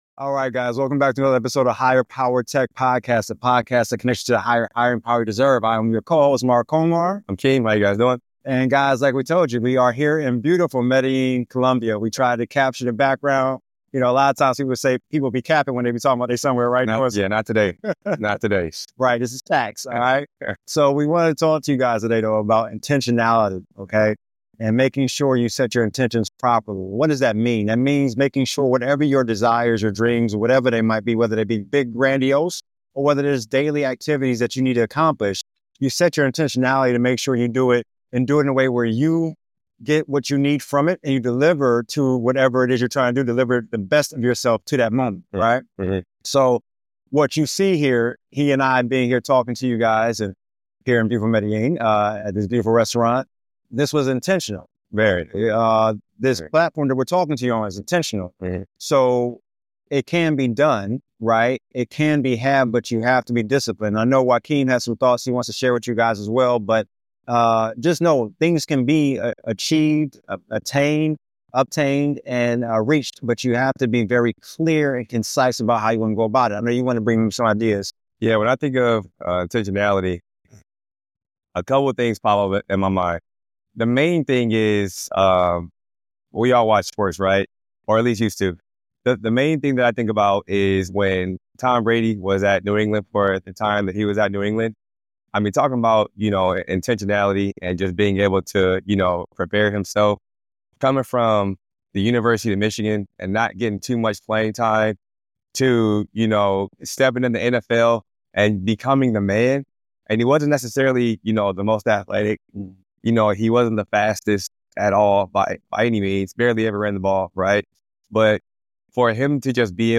EP#012 - Summary In this episode, we explore the power of intentionality and how it shapes success in every area of life. Broadcasting from the beautiful backdrop of Medellin, Colombia, we reflect on setting clear intentions and executing plans with purpose.